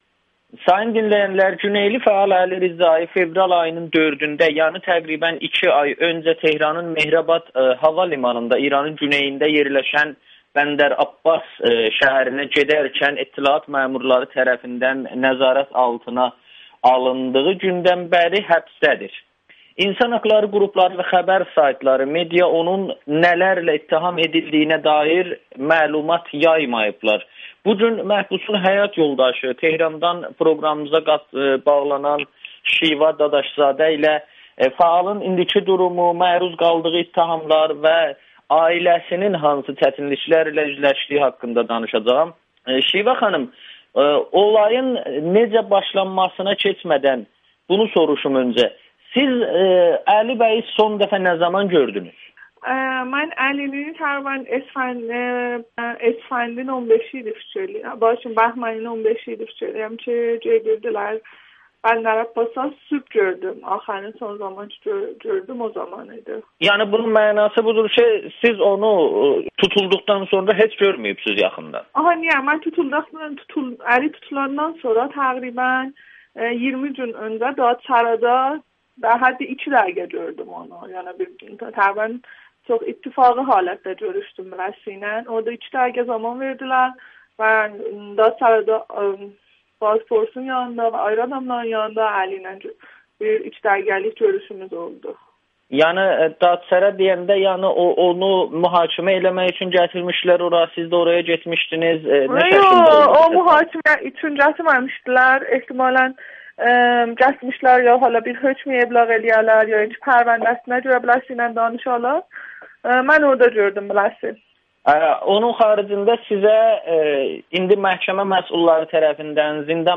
Güneyli məhbusun həyat yoldaşı: Müsahibə verdiyim üçün işdən çıxardılar [Audio]